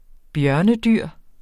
Udtale [ ˈbjɶɐ̯nə- ]